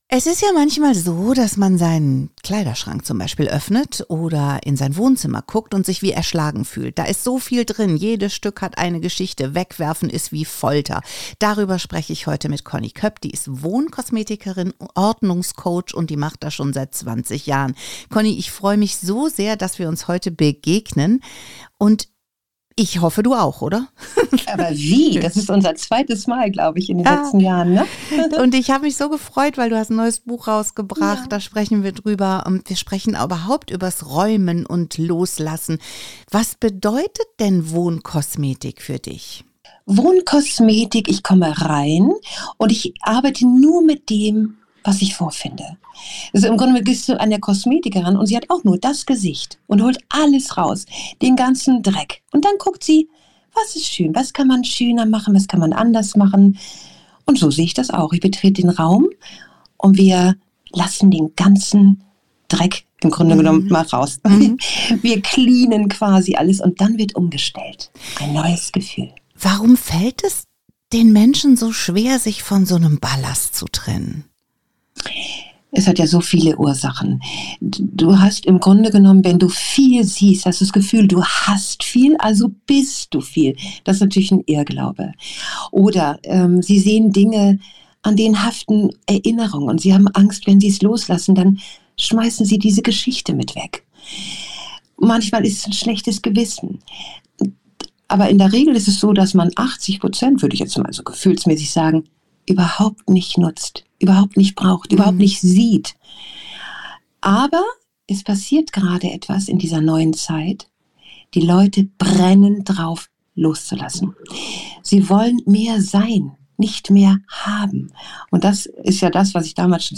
Buch zum Interview: